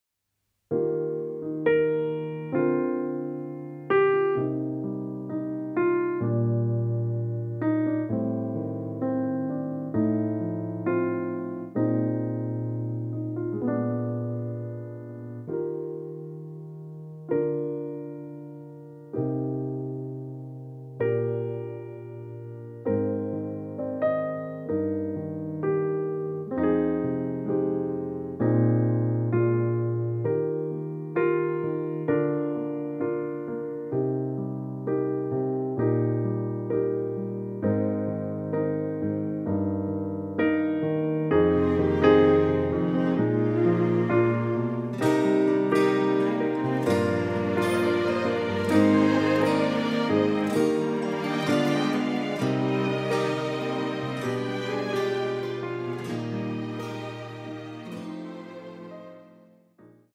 歌曲调式：升D调